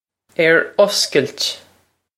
Air us-kilt
This is an approximate phonetic pronunciation of the phrase.